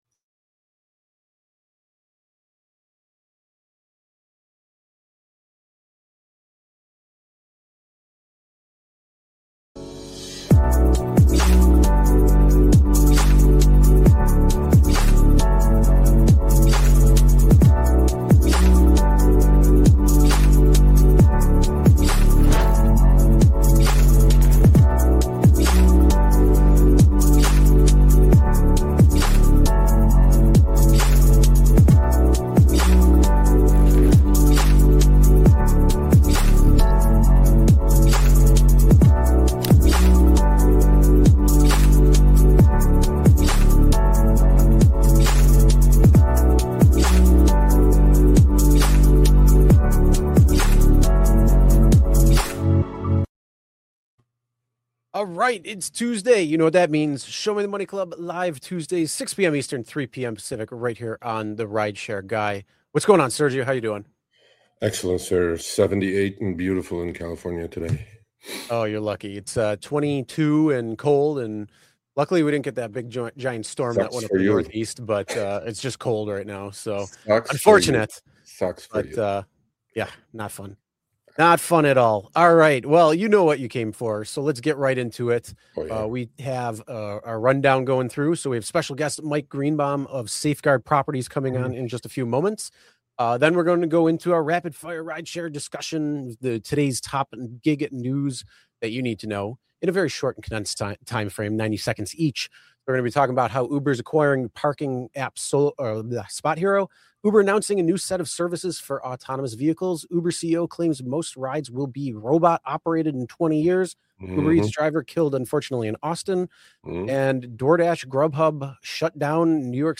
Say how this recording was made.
Show Me The Money Club is recorded live on YouTube from The Rideshare Guy.